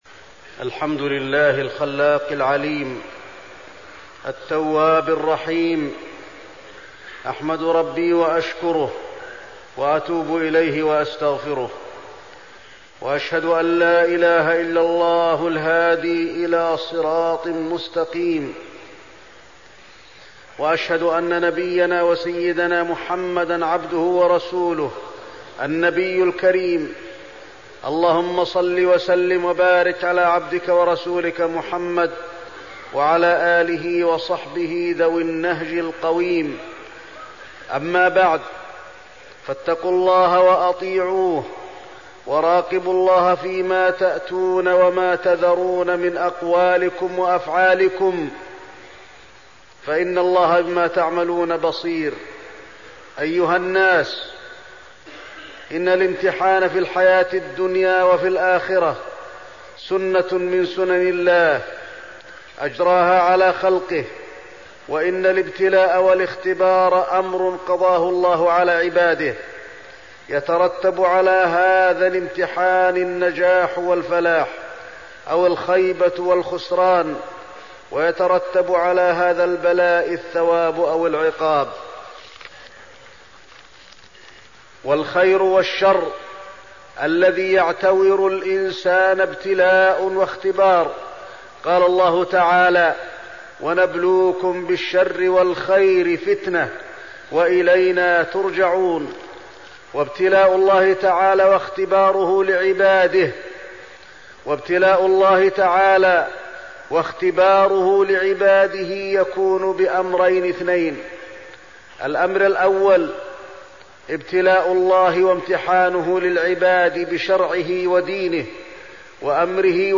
تاريخ النشر ٢١ محرم ١٤١٧ هـ المكان: المسجد النبوي الشيخ: فضيلة الشيخ د. علي بن عبدالرحمن الحذيفي فضيلة الشيخ د. علي بن عبدالرحمن الحذيفي الابتلاء